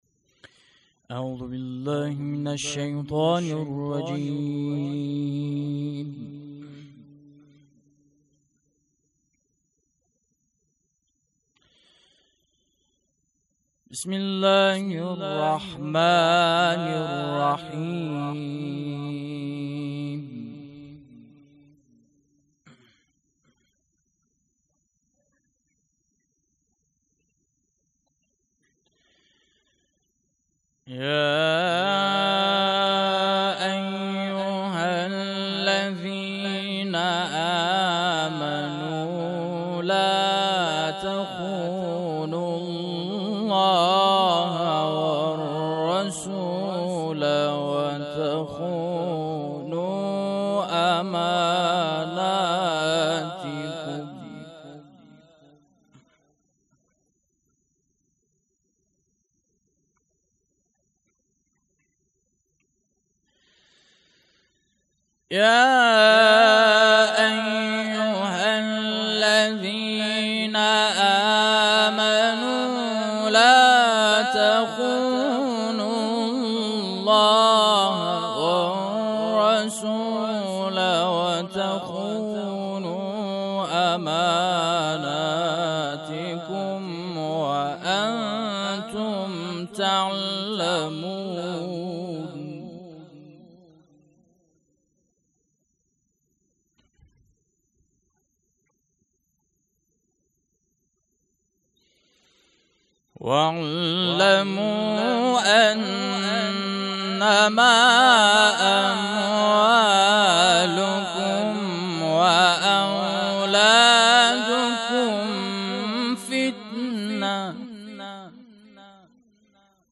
هیئت عقیله بنی هاشم سبزوار
شهادت حضرت ام البنین سلام الله علیها- آذرماه ۱۴۰۳